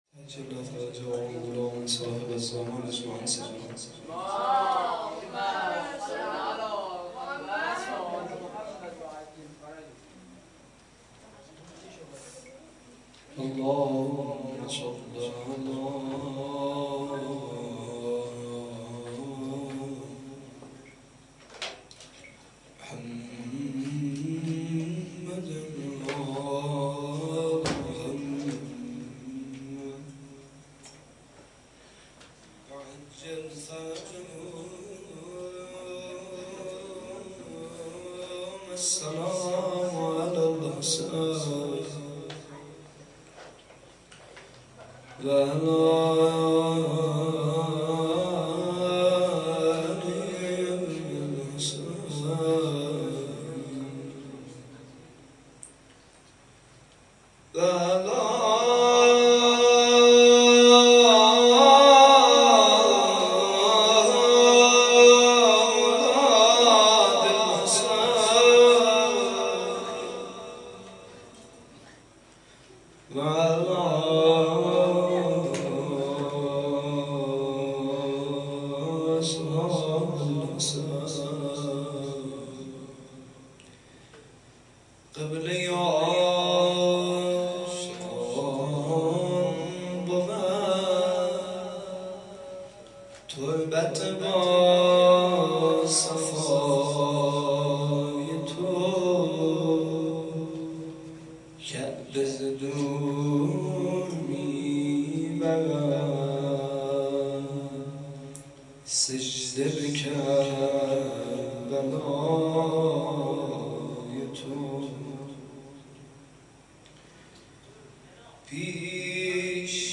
شب ۲۱ محرم 97
زمزمه